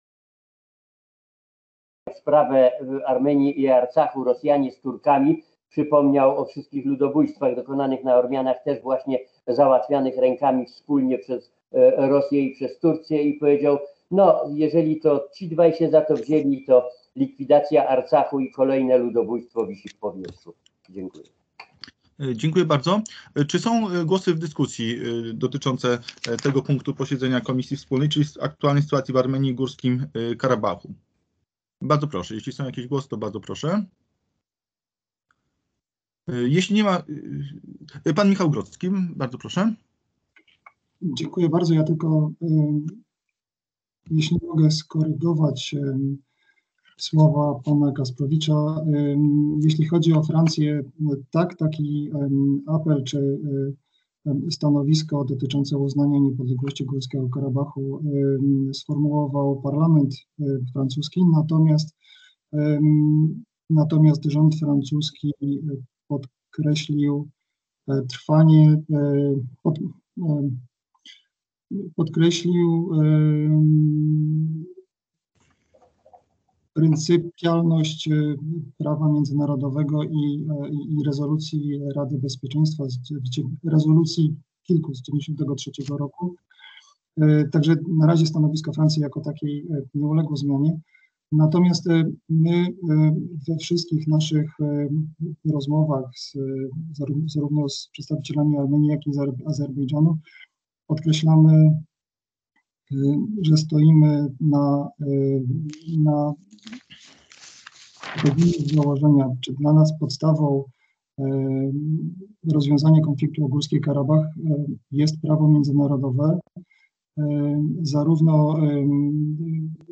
Nagranie z LXXII posiedzenia KWRiMNiE - 13.01.2021 - cz.3